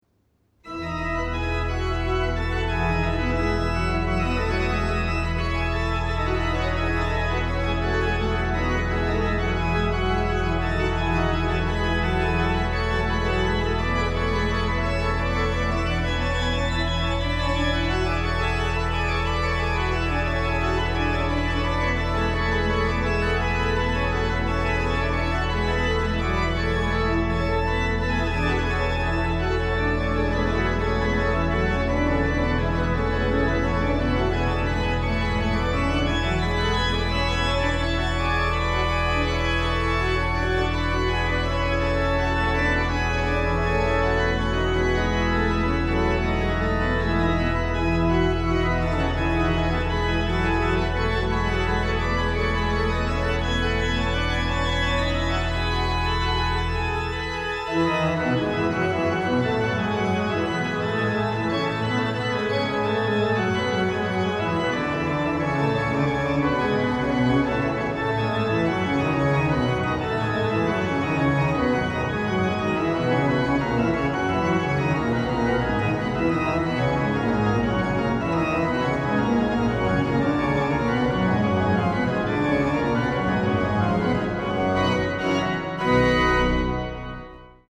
What better instrument to play it on other than the splendid new Bach organ in Thomaskiche Leipzig.
Registrations are clear, incisive and perfectly balanced, full of colour and show off the wonderful voicing of the instrument.